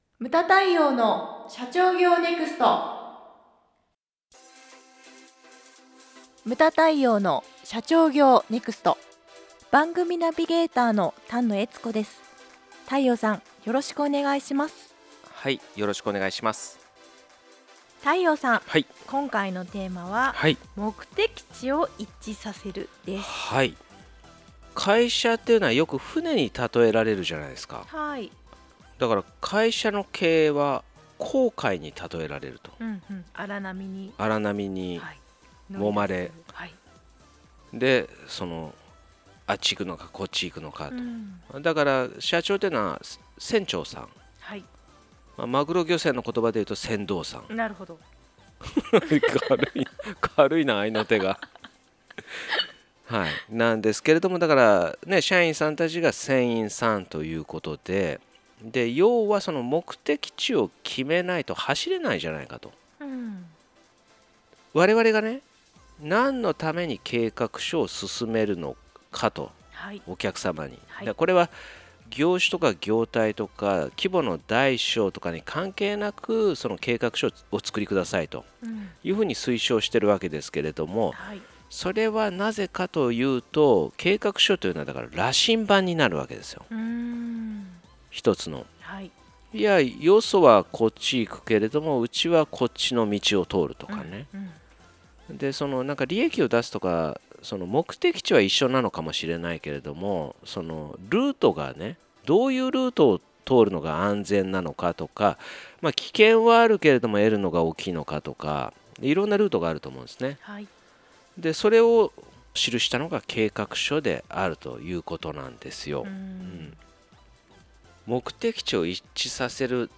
↑音声コラムは上記を再生ください↑